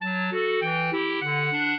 clarinet
minuet11-2.wav